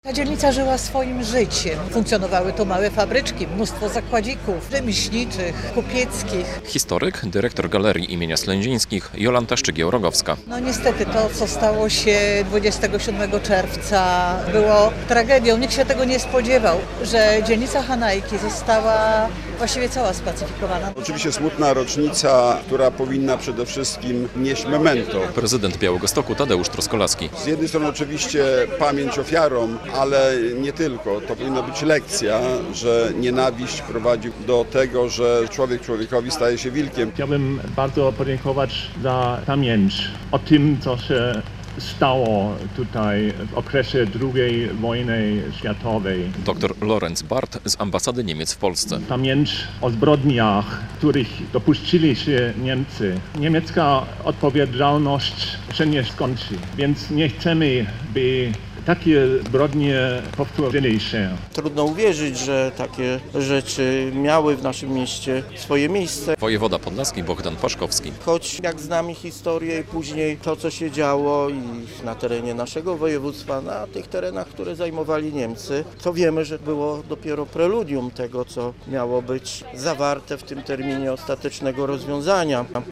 82. rocznica spalenia Wielkiej Synagogi w Białymstoku - relacja
Nawet niebo płacze nad tymi wydarzeniami (uroczystość odbywała się w deszczu - PAP); jest to rocznica jednego z najbardziej dramatycznych wydarzeń w dziejach naszego miasta - mówił w oficjalnym przemówieniu prezydent Białegostoku Tadeusz Truskolaski.